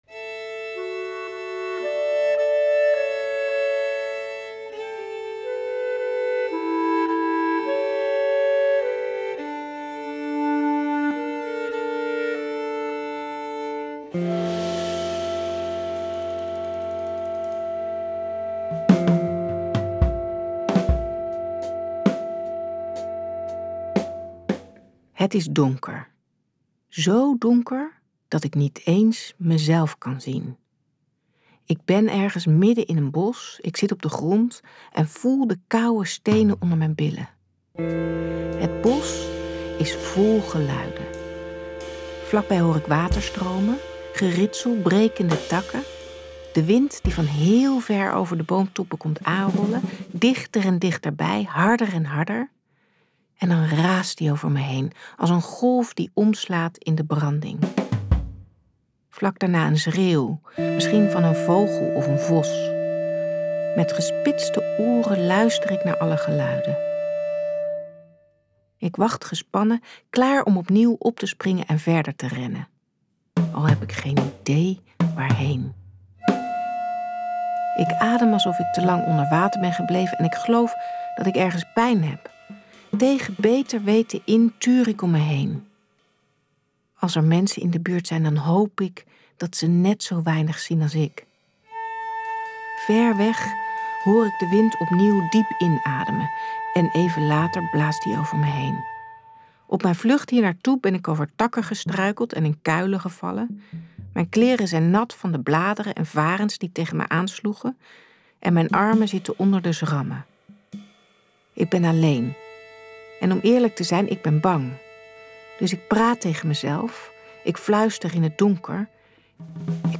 Een aangrijpend en ontroerend luisterboek over pesten.
met muziek van muziektheatergroep Ausdauer.
In dit verrijkte luisterboek wordt het verhaal muzikaal ondersteund door muziektheatergroep Ausdauer.